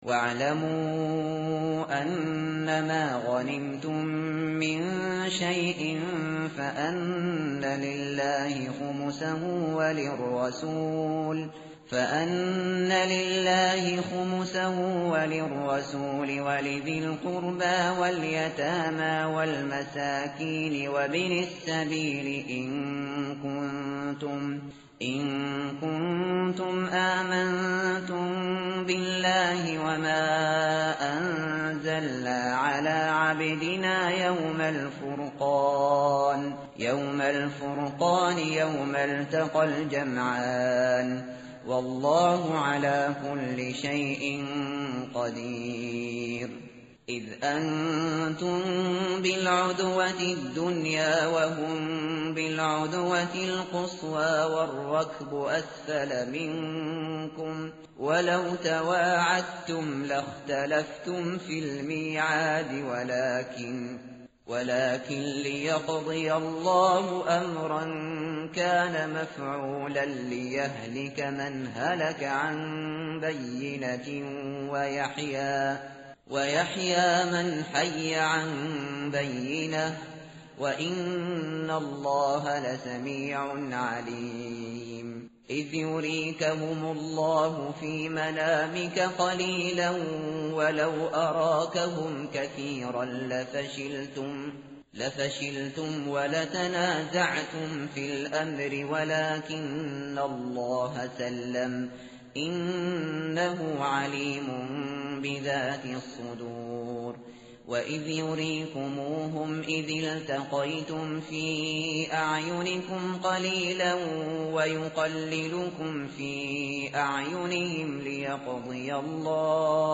tartil_shateri_page_182.mp3